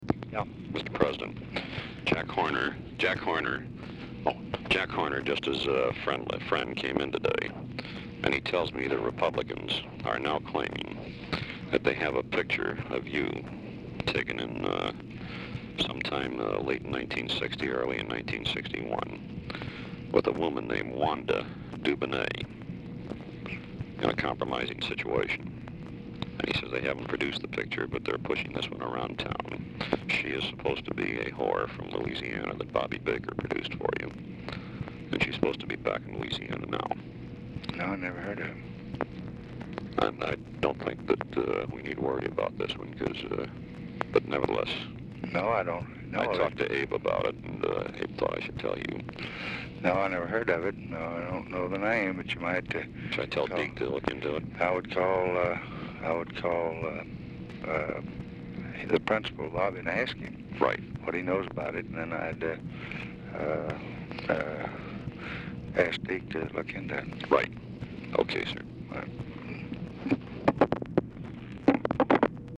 Telephone conversation # 5950, sound recording, LBJ and GEORGE REEDY, 10/23/1964, 6:24PM | Discover LBJ
Format Dictation belt
Location Of Speaker 1 Oval Office or unknown location